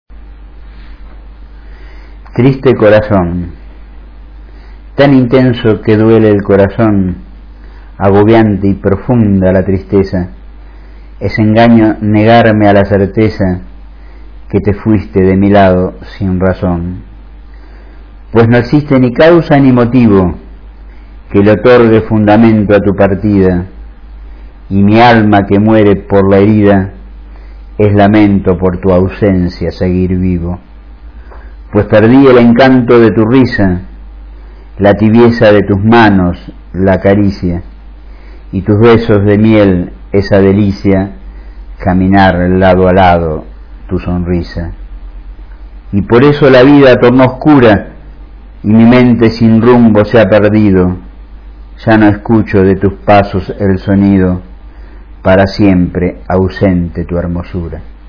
Recitado por el autor (0:57, 226 KB)